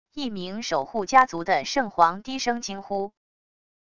一名守护家族的圣皇低声惊呼wav音频